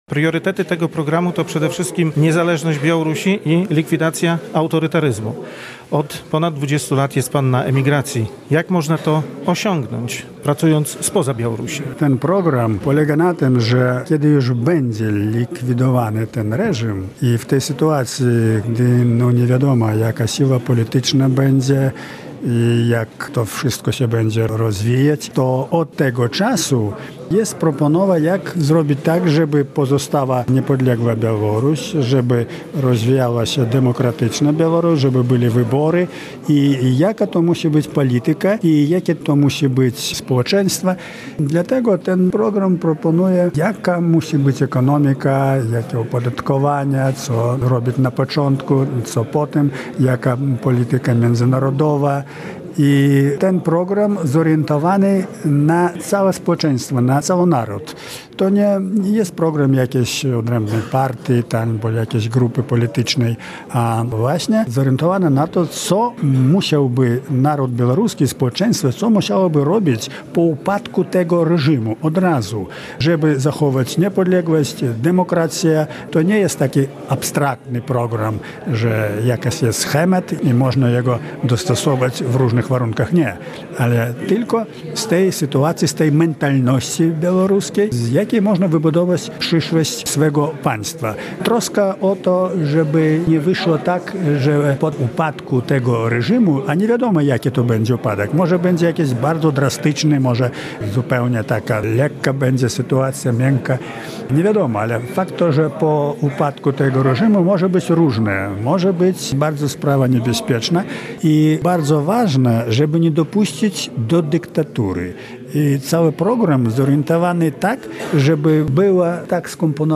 Radio Białystok | Gość | Zianon Paźniak - białoruski działacz opozycyjny